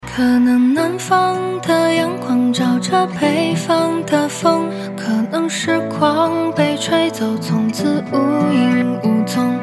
原声：